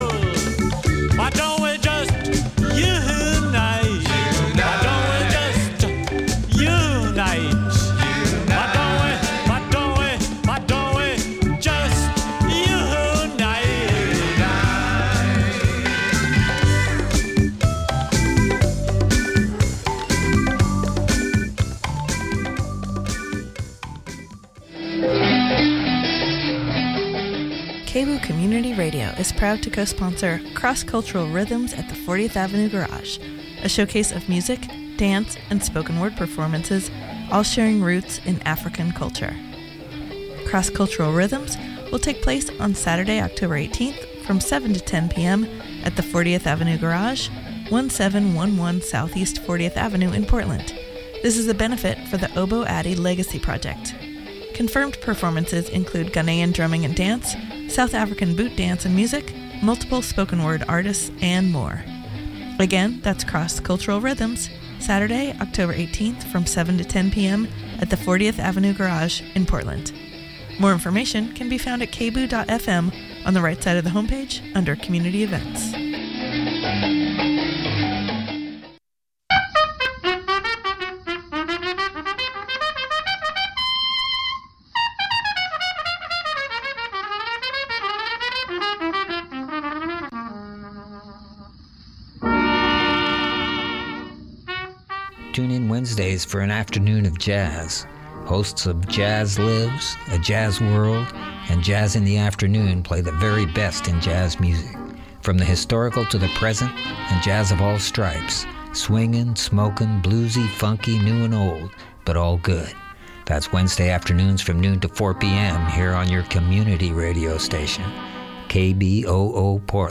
live in the studio for an exclusive discussion about their vision for change